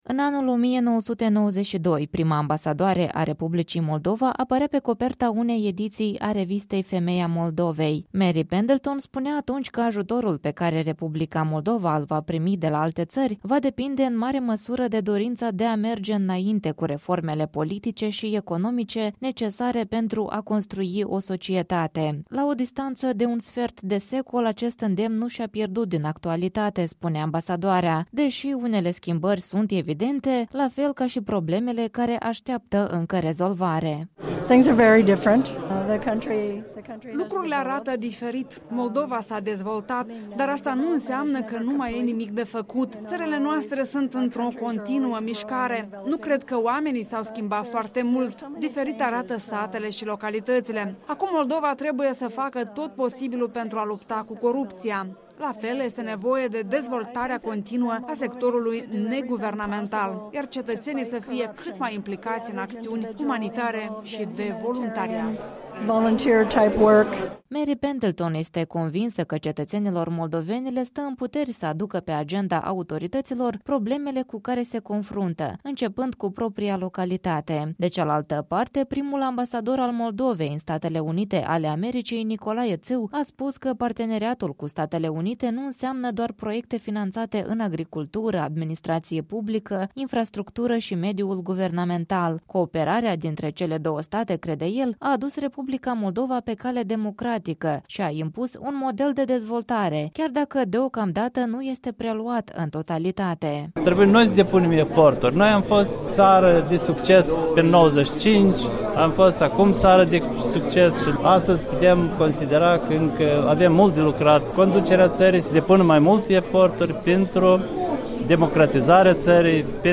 a stat de vorbă cu ambasadoarea, la o expoziție de fotografii și documente consacrată acestor relații.